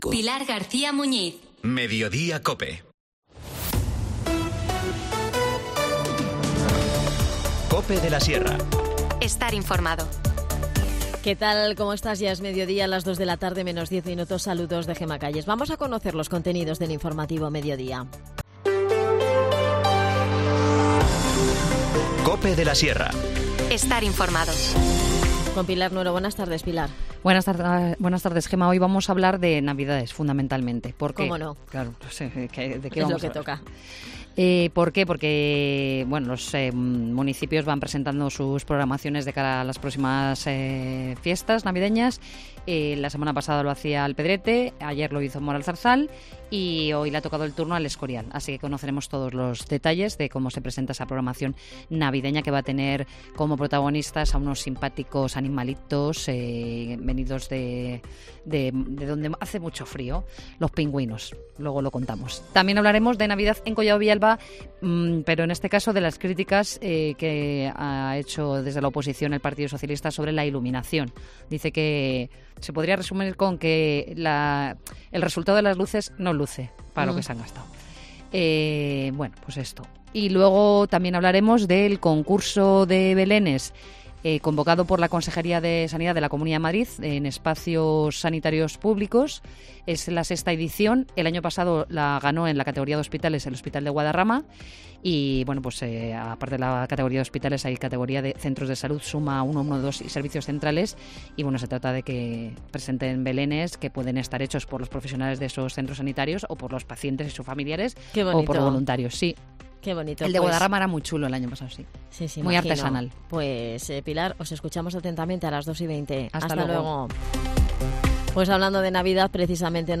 Nos ha dado todos los detalles Juan José Cámara, concejal de Cultura.